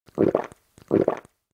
Звуки питья в Майнкрафт весьма специфичны и совсем не похожи на реальные звуки.
Пьет большими глотками
Drinking-sound-effect-256-kbps.mp3